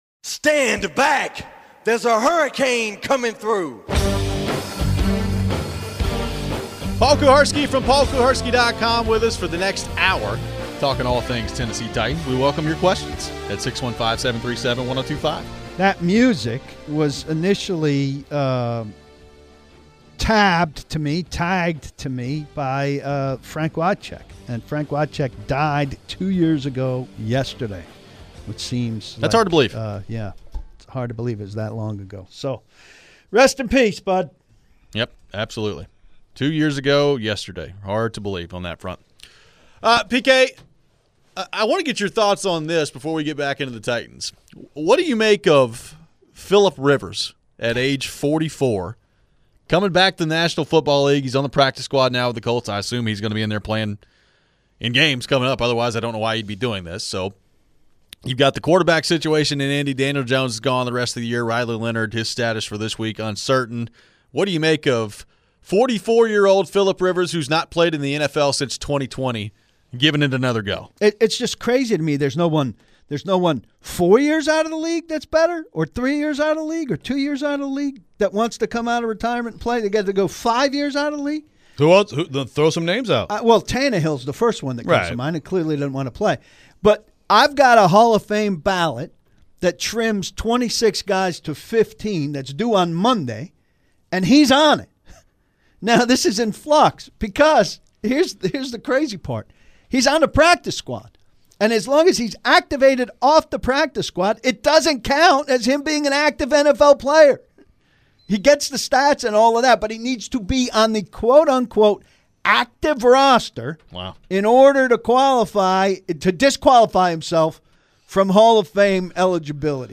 We head to the phones.